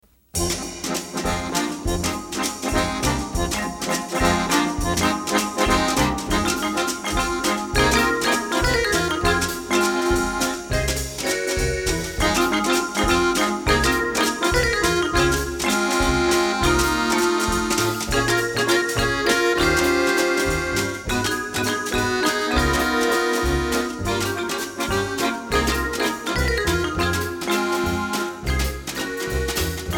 an outstanding example of jazz on the accordion